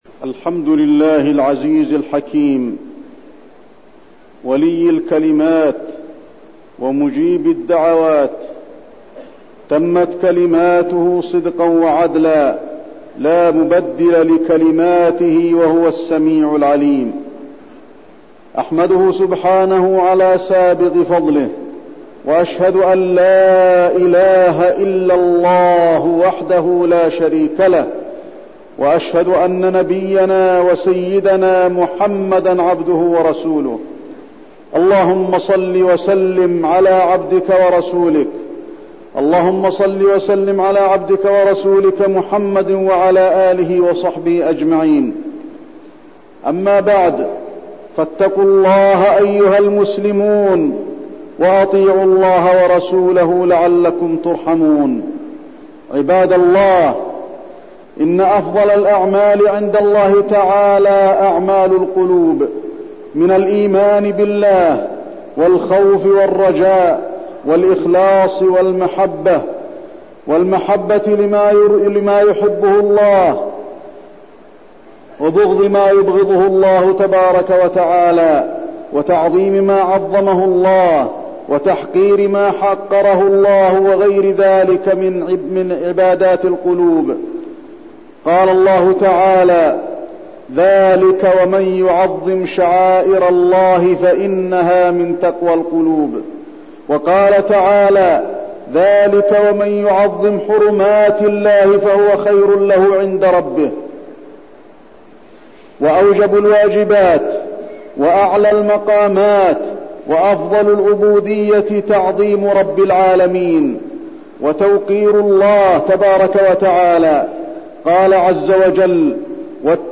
تاريخ النشر ١٣ ذو القعدة ١٤٠٩ هـ المكان: المسجد النبوي الشيخ: فضيلة الشيخ د. علي بن عبدالرحمن الحذيفي فضيلة الشيخ د. علي بن عبدالرحمن الحذيفي تعظيم رب العالمين